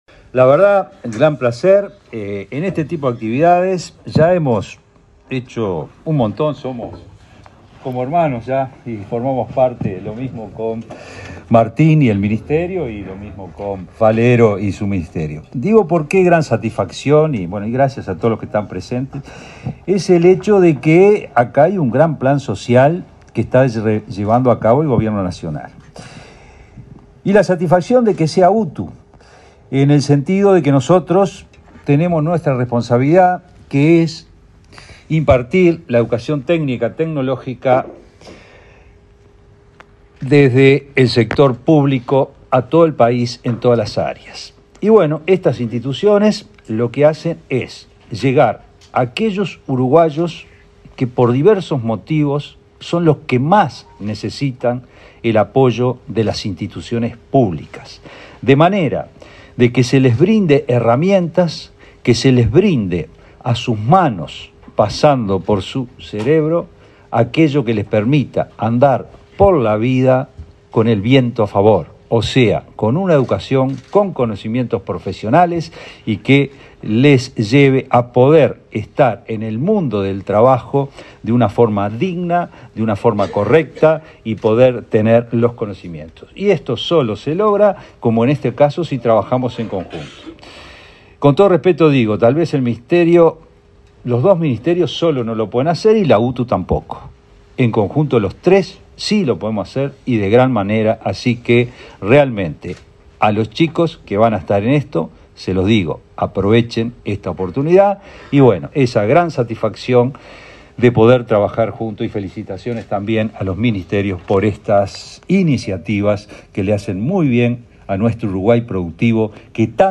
Palabras de autoridades en convenio entre UTU, MTOP y Mides
Palabras de autoridades en convenio entre UTU, MTOP y Mides 06/07/2023 Compartir Facebook X Copiar enlace WhatsApp LinkedIn Este jueves 6, el Ministerio de Transporte y Obras Públicas (MTOP), el de Desarrollo Social (Mides) y la UTU firmaron un convenio por el cual se formará a beneficiarios de programa Accesos en las oficinas de la Dirección Nacional de Arquitectura. El director de la UTU, Juan Pereyra, así como los ministros Martín Lema y José Luis Falero, destacaron la importancia del acuerdo.